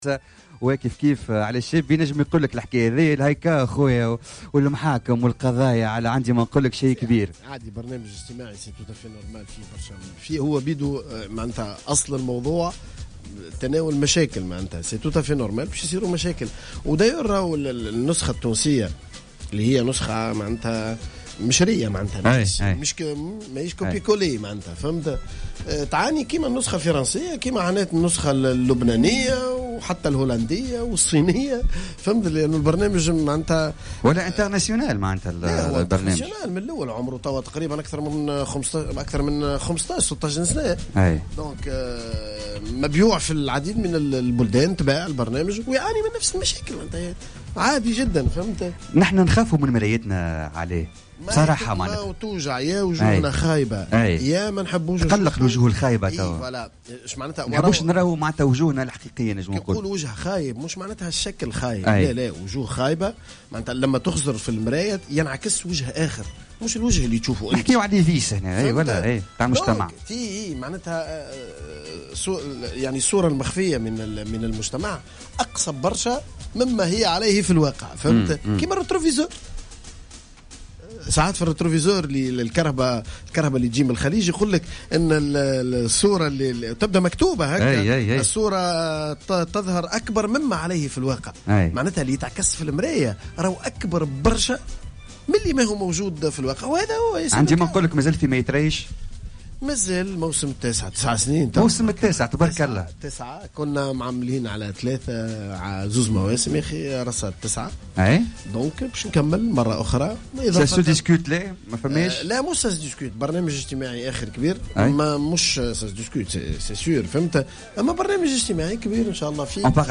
أكد الإعلامي علاء الشابي في تصريح للجوهرة "اف ام" اليوم الأحد 7 أوت 2016 أنه بصدد إعداد برنامج اجتماعي جديد سوف ينطلق بثه على الأرجح في الشبكة التلفزيونية القادمة .